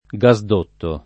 gasdotto [ g a @ d 1 tto ] s. m.